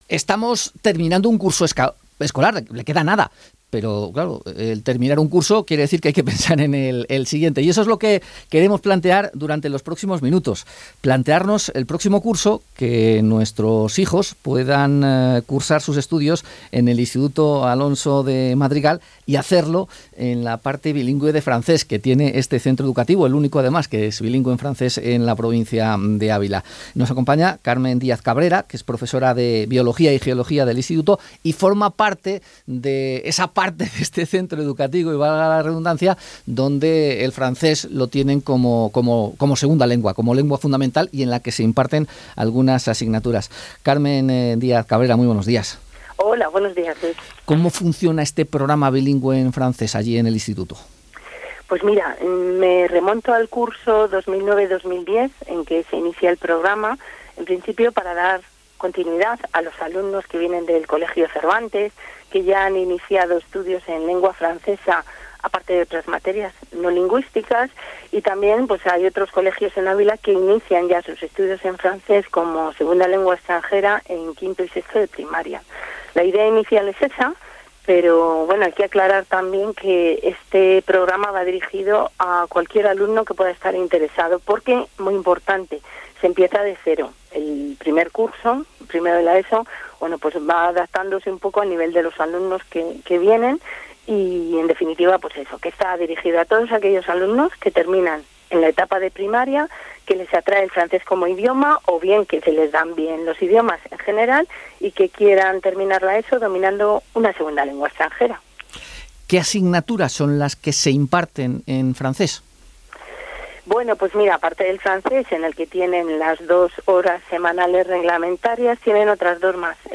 Podcast_ENTREVISTASECCION_BILINGUE_FRANCES-_LA_SER.mp3